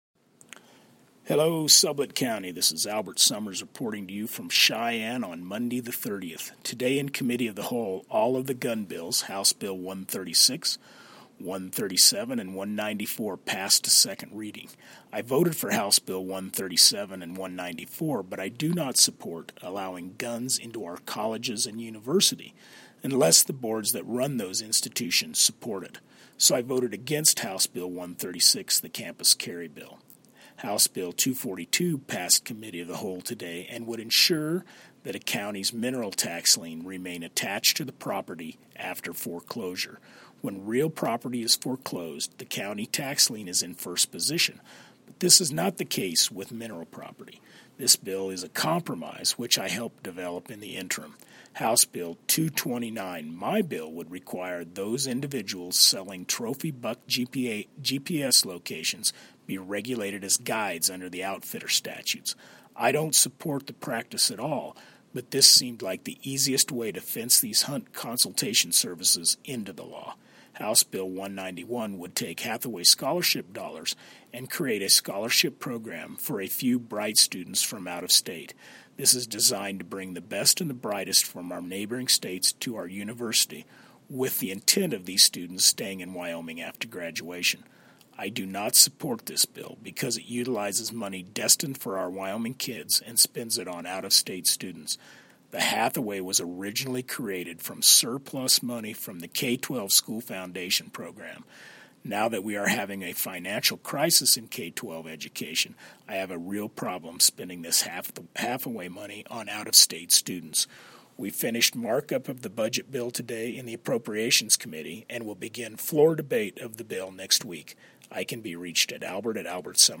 by Albert Sommers, House District #20 Representative